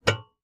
tr_com_gasdoor_open_01_hpx
Nissan Sentra exterior point of view as gas cap and door opens and releases pressure.